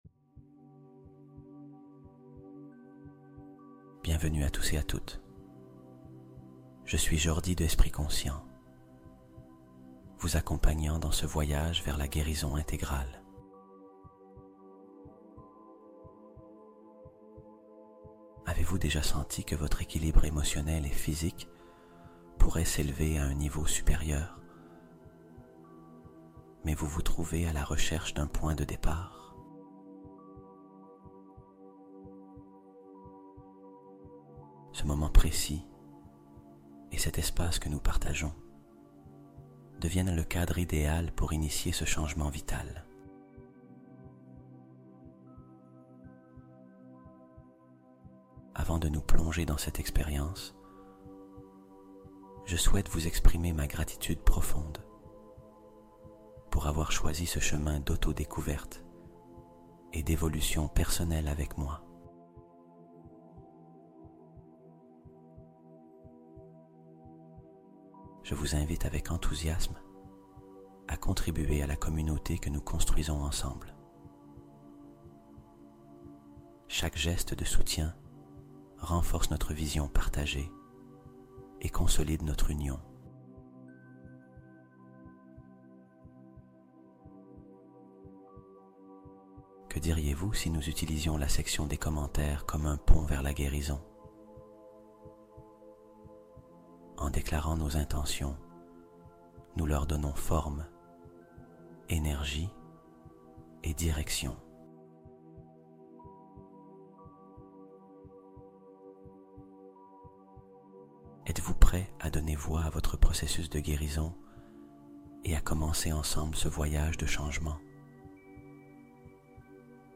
Guérison quantique instantanée | Hypnose pour sommeil réparateur et soulagement total